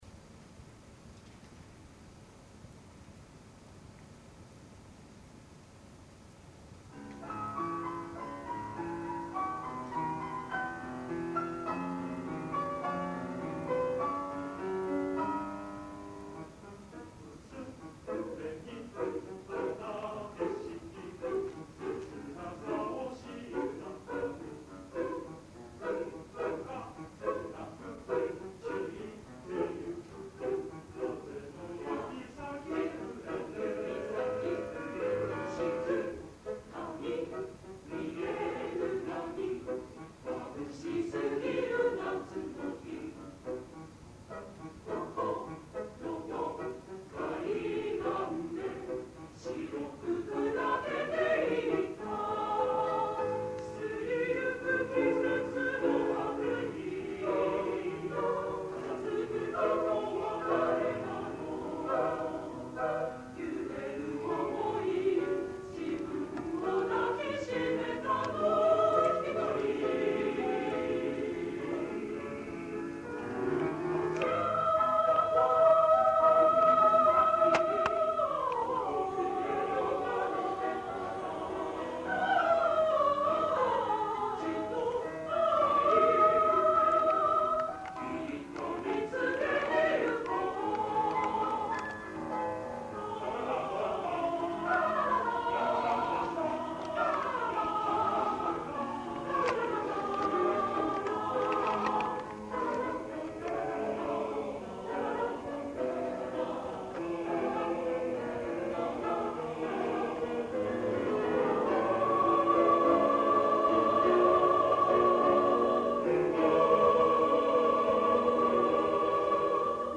１９９９年６月１３日　東京・中野ゼロホールで
ソプラノ５名、アルト５名、テナー４名、ベース３名の
編成です。男性１名カウンターテナーがアルトです。
お母様が小さなラジカセでテープ録音をして頂き、
音量、音質はご勘弁下さい。
ピアノ
合唱団　某大学ＯＢ会合唱団メンバー　１７名